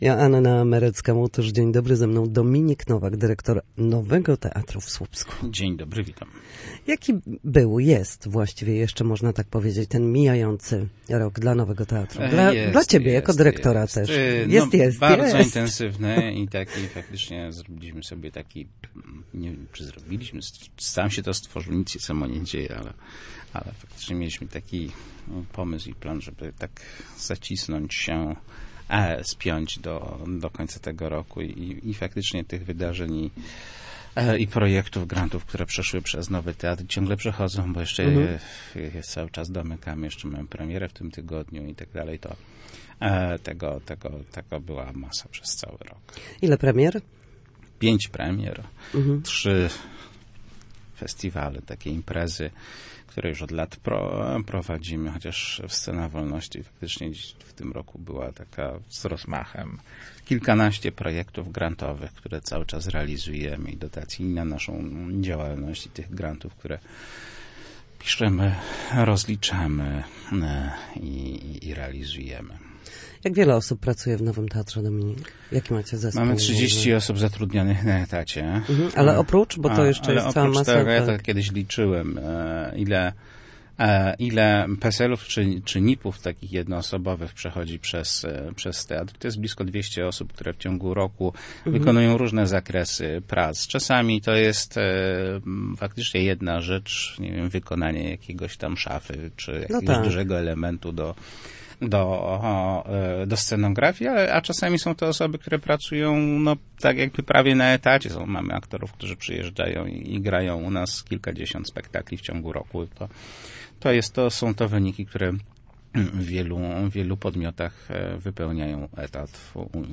Na naszej antenie mówił o pracy teatru, dotacjach, projektach i nadchodzącej premierze.